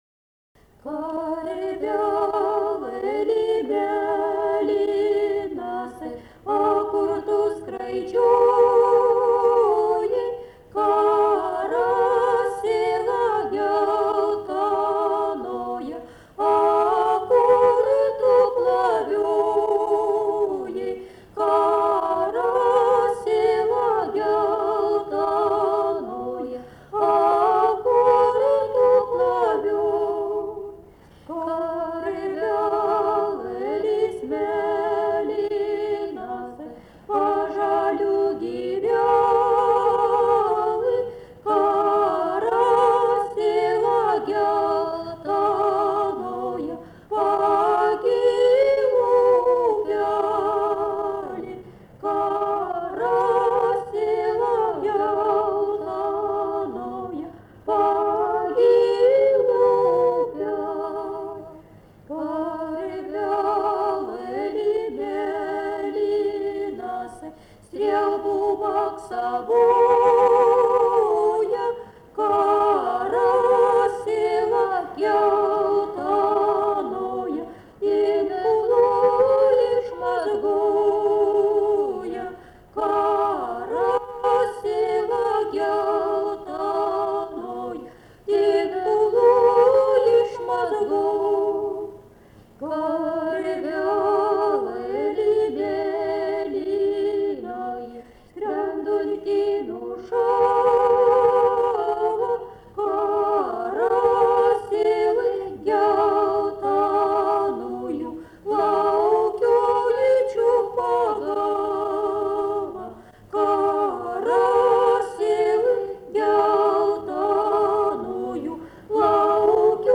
daina, vaikų
Atlikimo pubūdis vokalinis